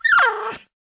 Index of /App/sound/monster/misterious_diseased_dog
dead_1.wav